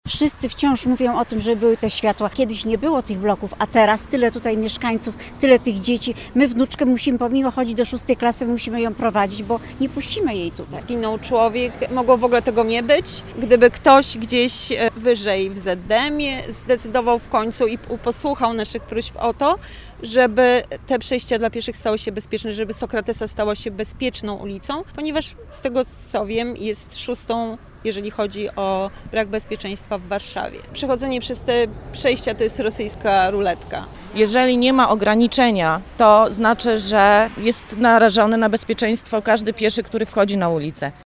– Wbrew ich postulatom, wciąż nie ma tam sygnalizacji świetlnej. Te przejście jest dramatyczne – mówią mieszkańcy.